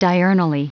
Prononciation du mot diurnally en anglais (fichier audio)
Prononciation du mot : diurnally